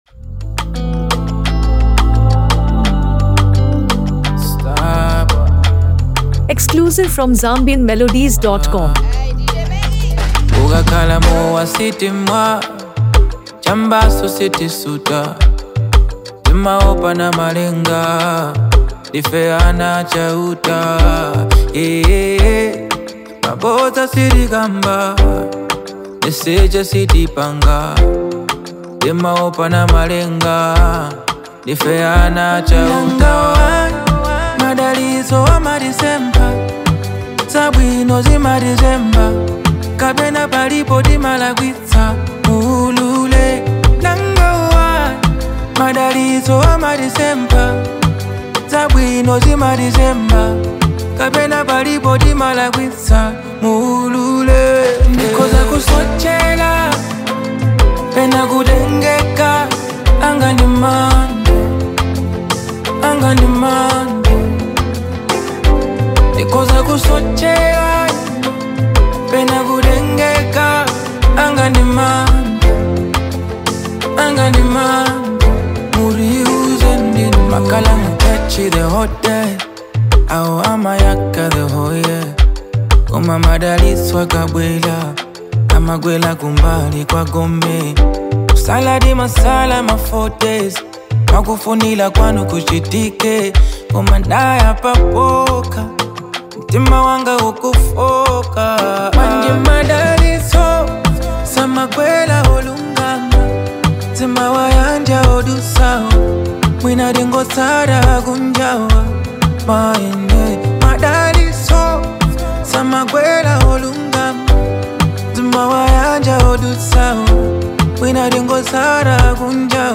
Known for his soulful voice and authentic storytelling
Afrobeat, R&B, and local Malawian rhythms
Through touching lyrics and smooth melodies